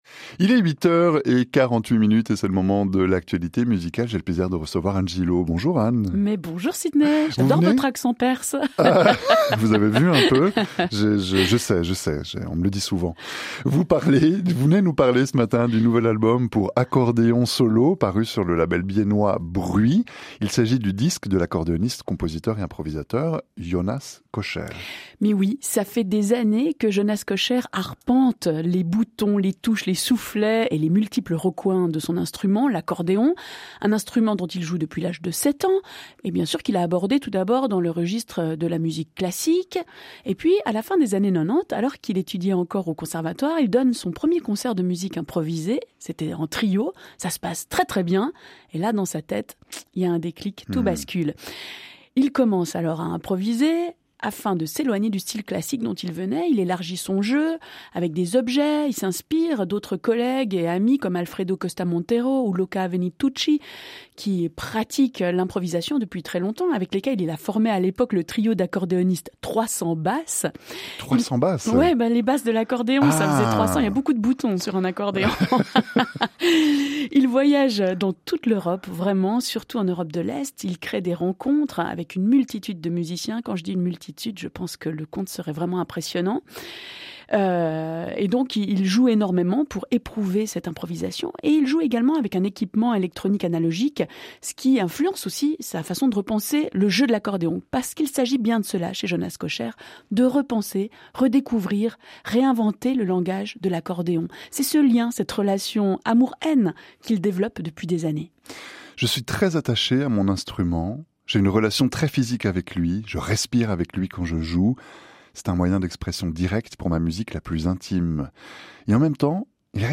Across seven improvised pieces
accordion Bugari Bayan 2RC
Recorded February 26 2025 in Biel/Bienne, Switzerland.